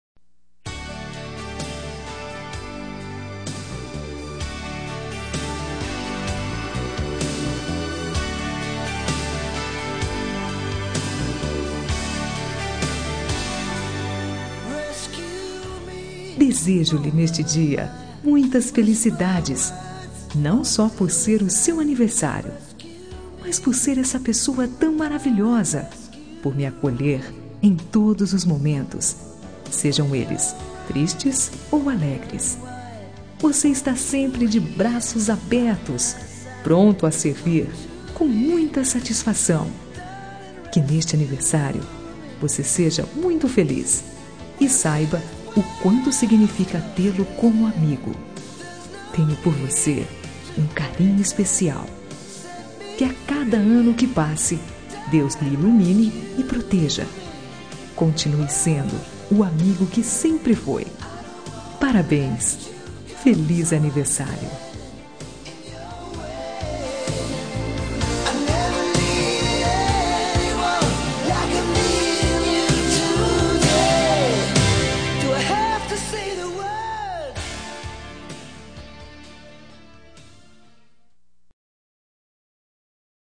Telemensagem de Aniversário de Amigo – Voz Feminina – Cód: 1550